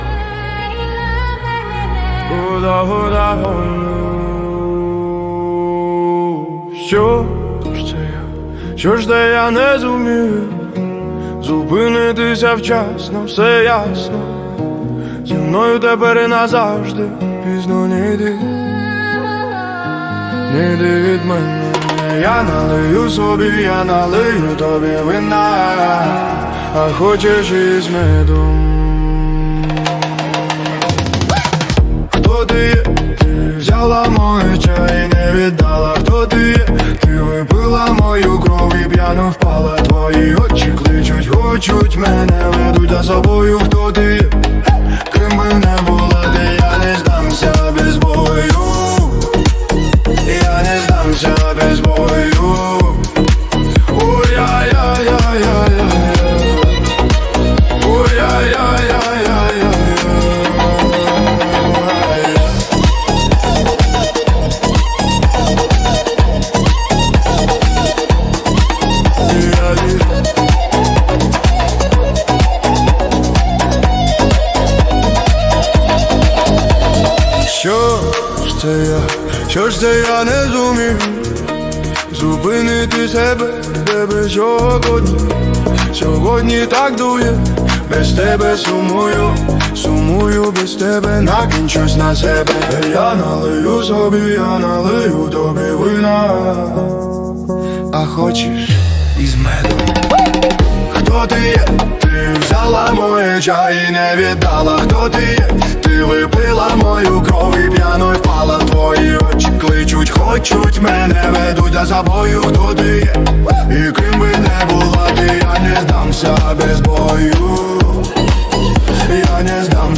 Afro House Сover